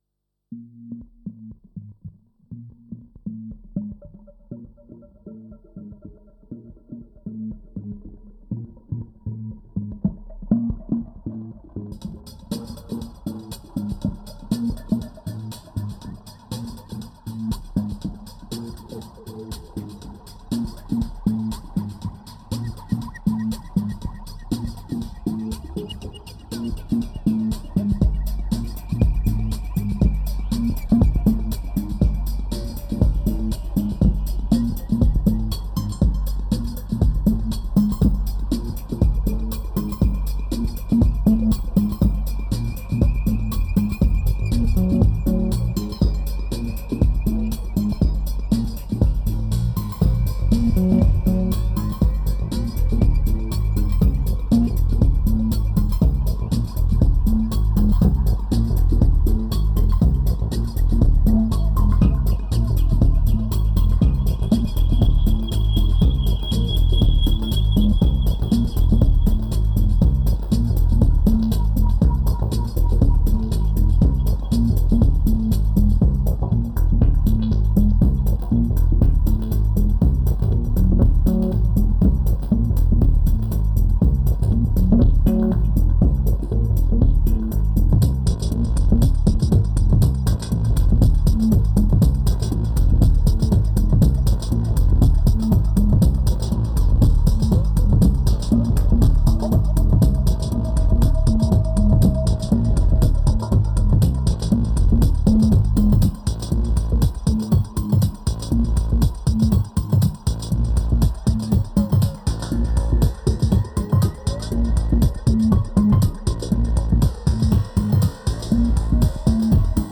2397📈 - 21%🤔 - 120BPM🔊 - 2011-06-19📅 - -74🌟
Bass Solo Questions Noises Broken Trigger Electronica